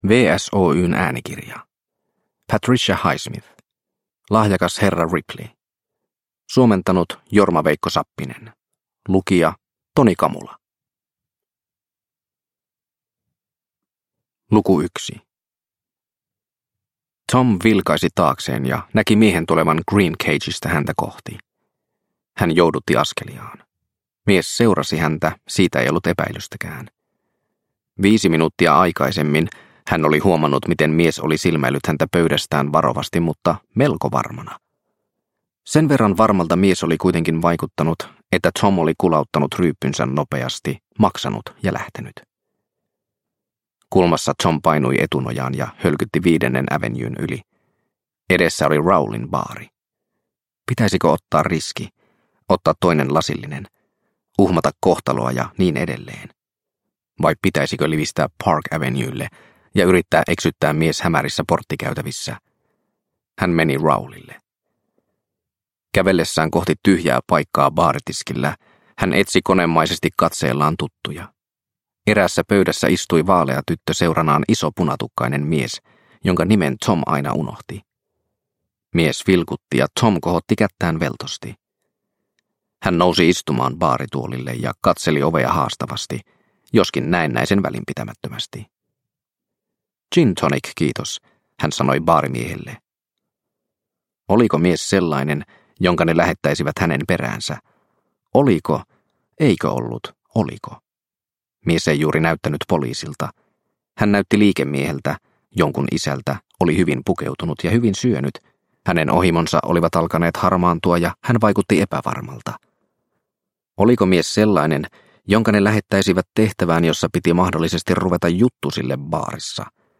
Lahjakas herra Ripley – Ljudbok – Laddas ner